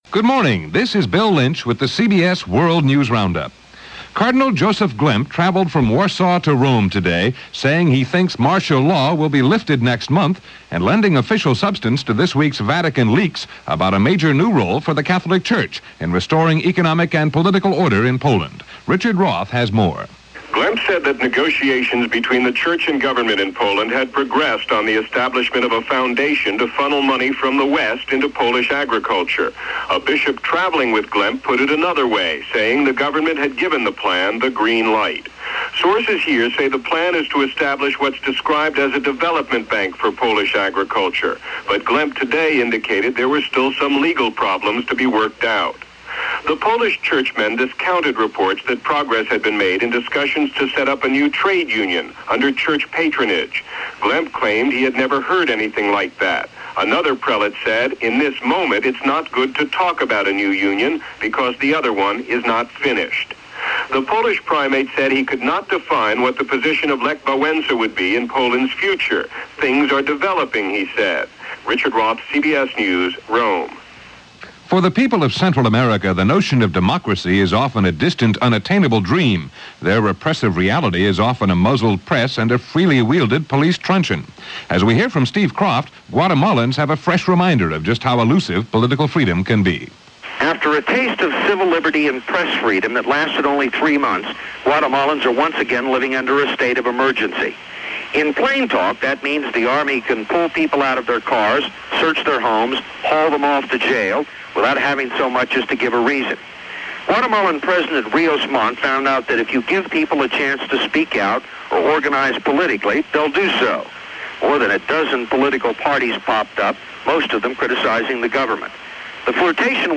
June 30, 1983 – CBS World News Roundup + Newsbreak + 9:00 network news –